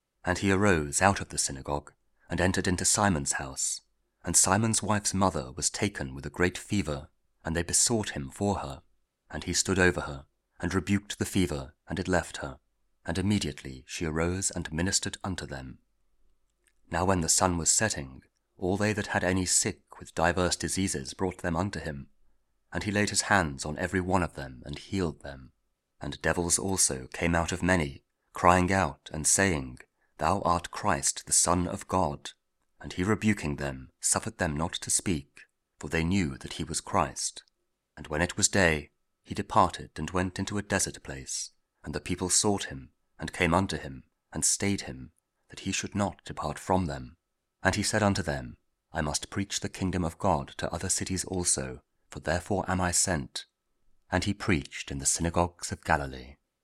Luke 4: 38-44 – Week 22 Ordinary Time, Wednesday (King James Audio Bible KJV, Spoken Word)